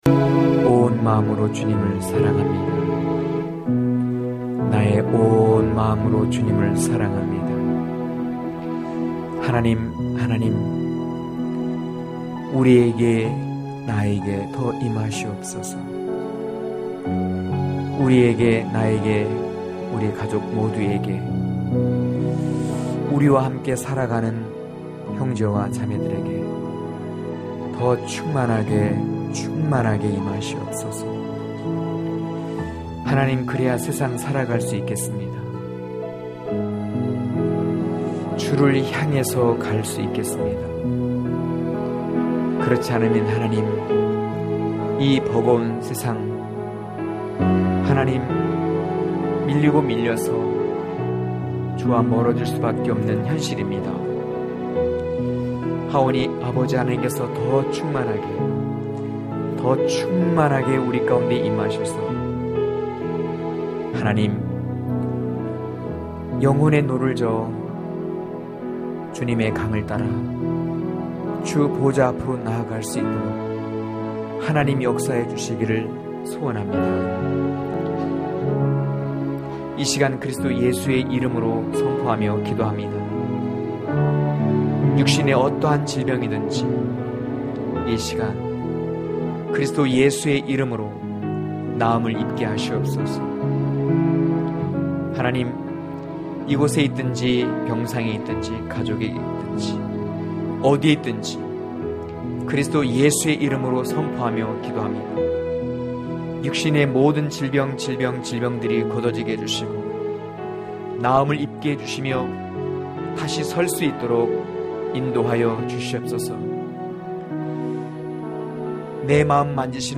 강해설교 - 07.에덴 안으로...(아2장8-13절)